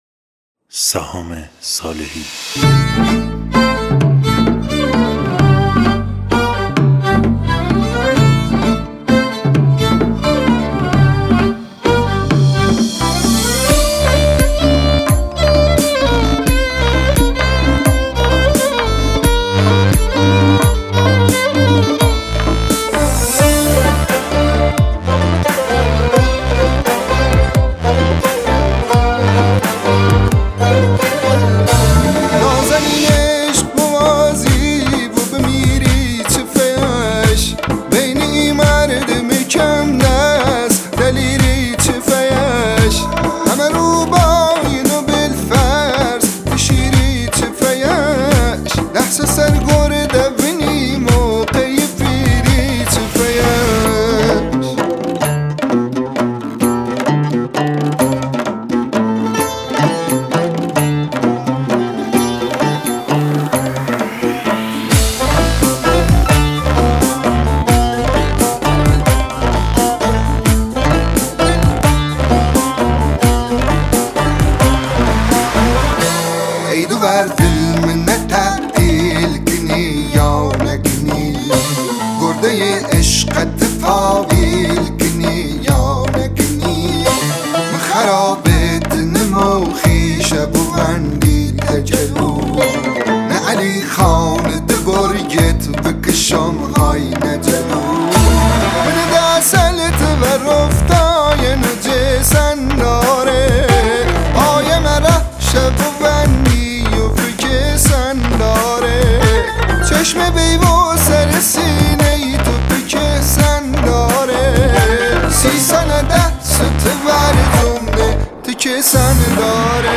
دانلود آهنگ لری